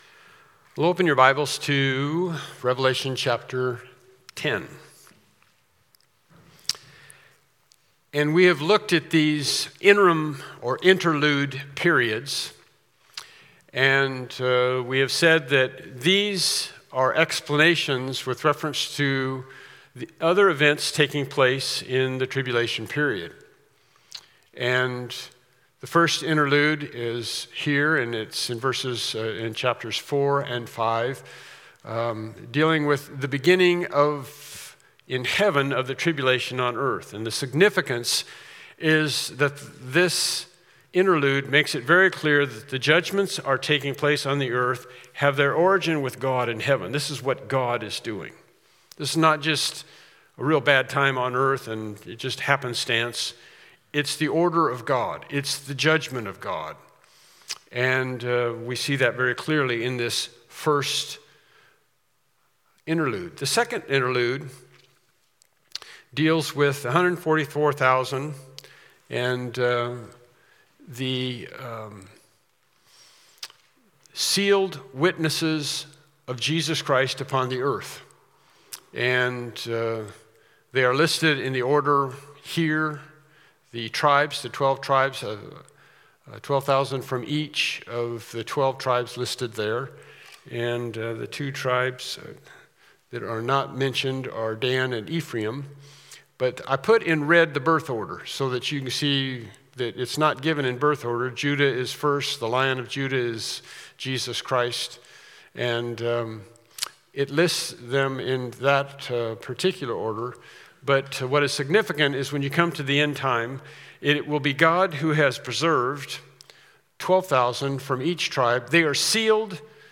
Revelation 10-11 Service Type: Evening Worship Service « “Put Off